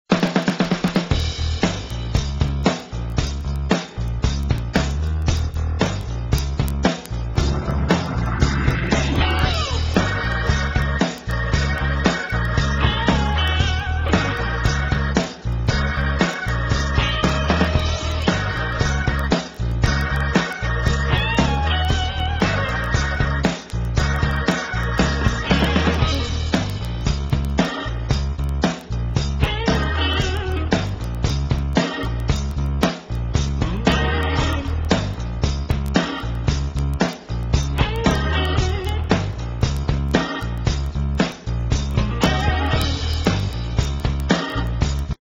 NOTE: Background Tracks 9 Thru 16